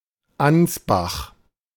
Ansbach (/ˈænzbæk/ ANZ-bak, German: [ˈansbax]
De-Ansbach.ogg.mp3